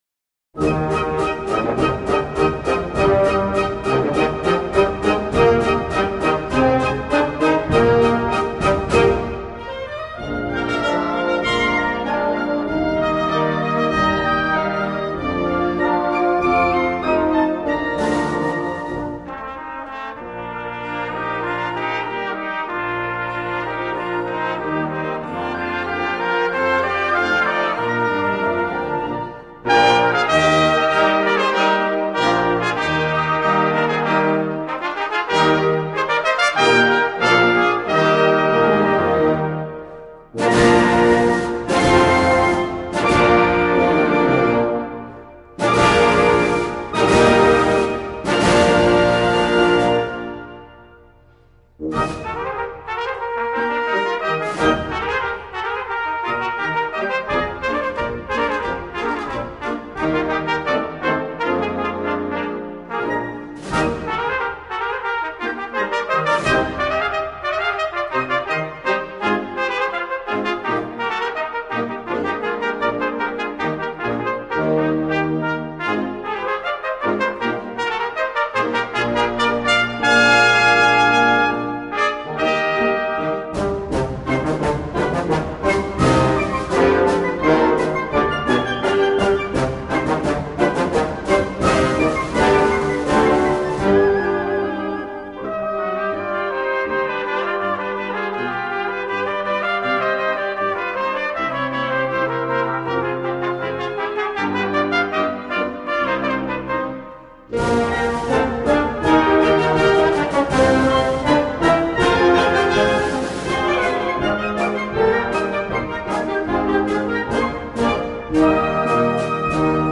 Instrumental Concert Band Section/Solo Features
Concert Band